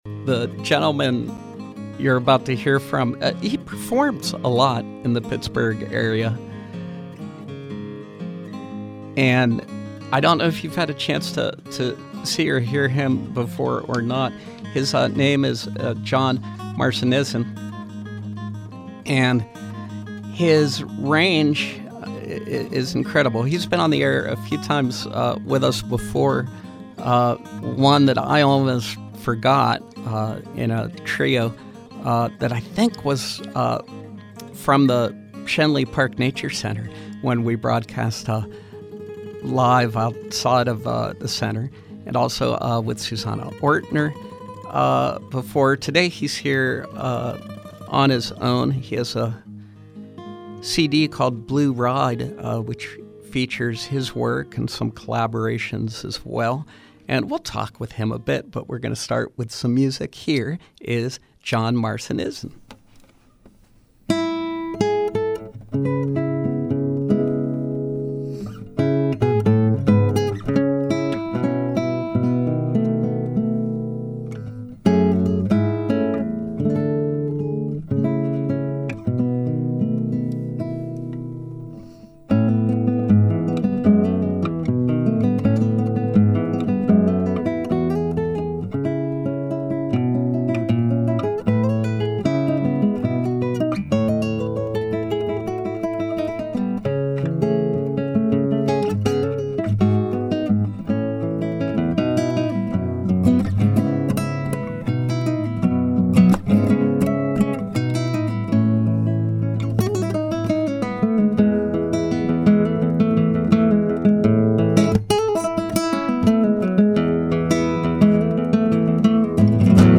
live acoustic guitar selections